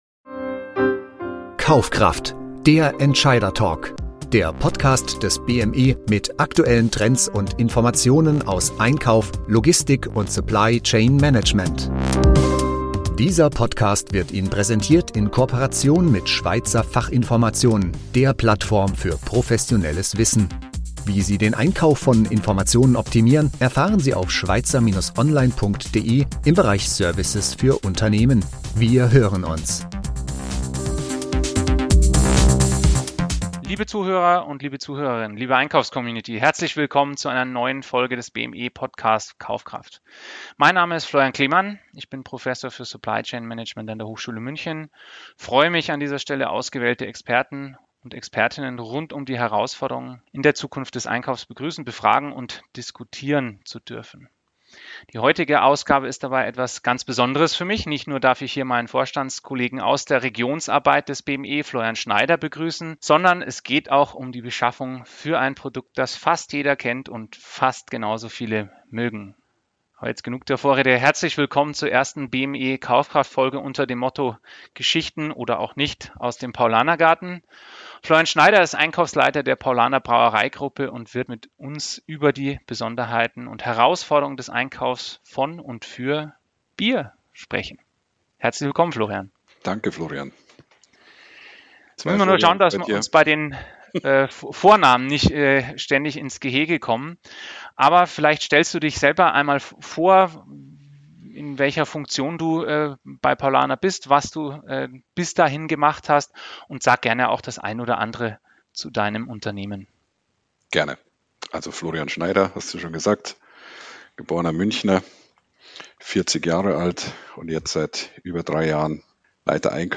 Dieses Interview gewährt Einblicke in eine Branche, die nicht nur mit wirtschaftlichen Herausforderungen konfrontiert ist, sondern auch mit den Auswirkungen des Klimawandels kämpft.